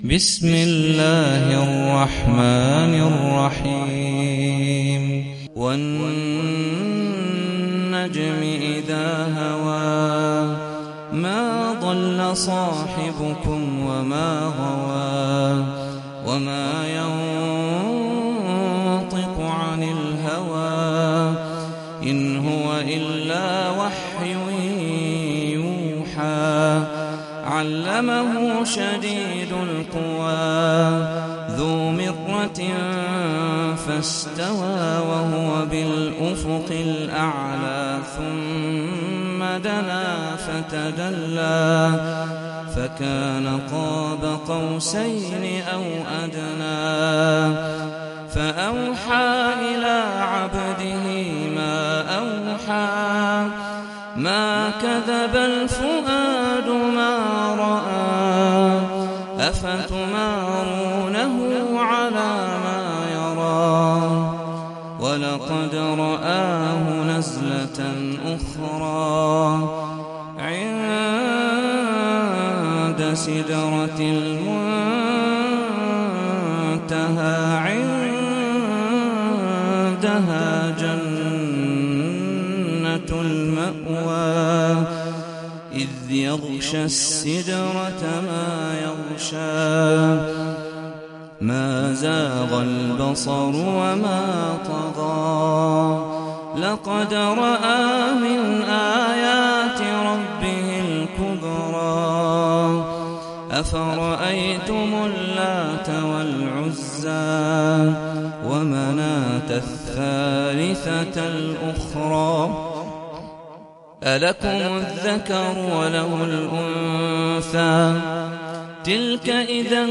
Sûrat An-Najm (The Star) - صلاة التراويح 1446 هـ (Narrated by Hafs from 'Aasem